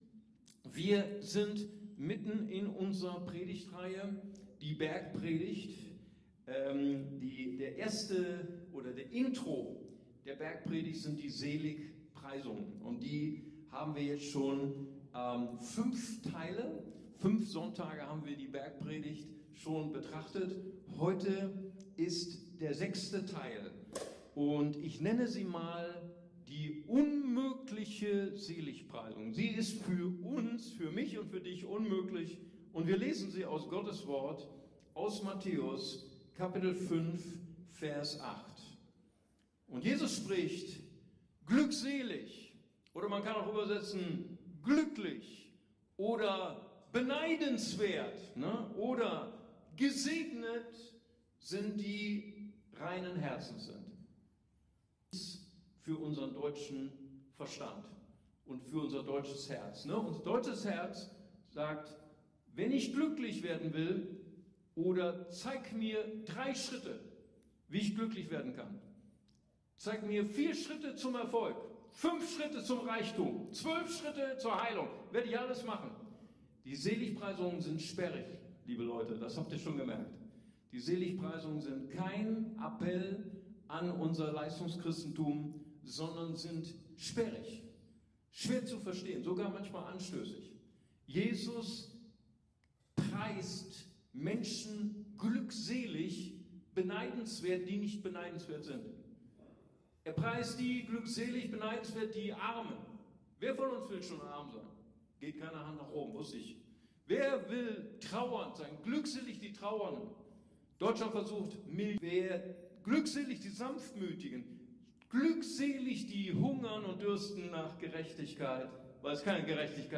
Predigtreihe: Bergpredigt: Die 8 Türen zum Glück!